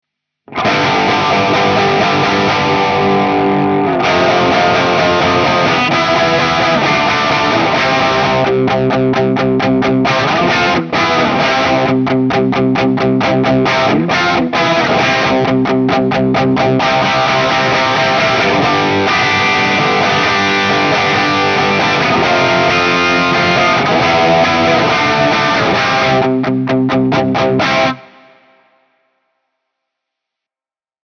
Tutte le clip audio sono state registrate con amplificatore Fender Deluxe e una cassa 2×12 con altoparlanti Celestion Creamback 75.
Clip 7 – Les Paul, Max Gain, Over Drive as Boost, amp on breakup
Chitarra: Gibson Les Paul (Pickup al ponte)
LP-Boost.mp3